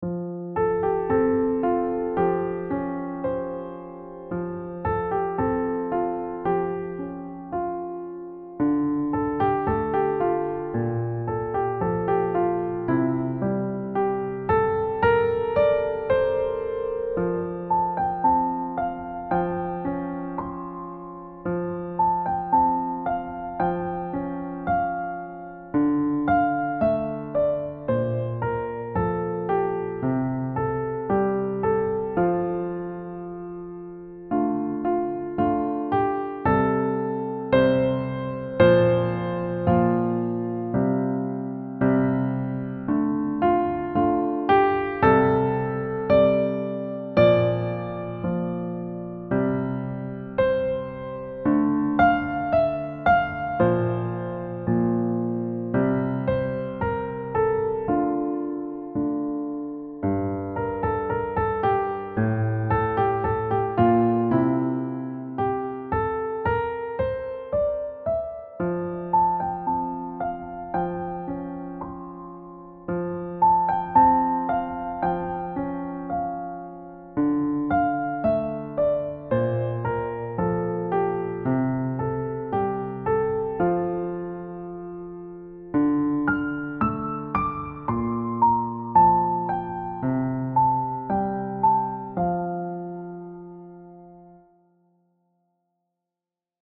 piano solo
Key: F Major with accidentals
Time Signature: 4/4 (BPM ≈ 112)